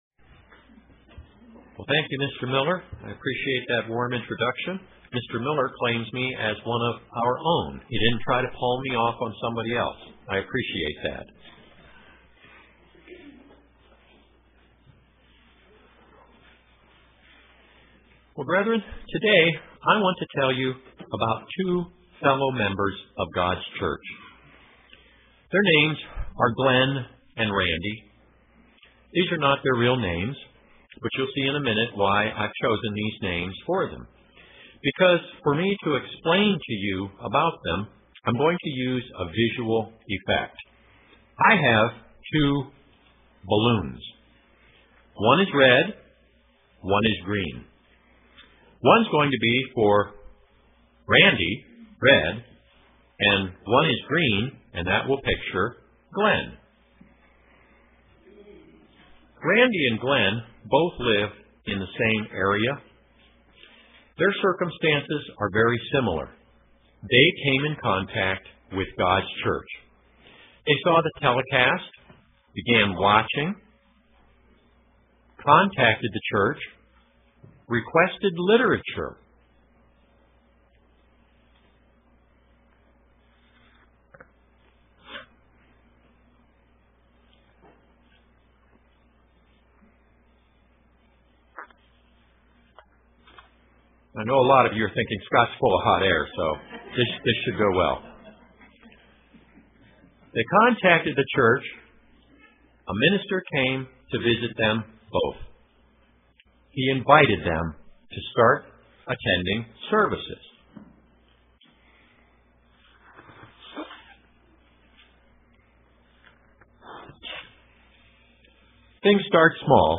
Print Untitled UCG Sermon Studying the bible?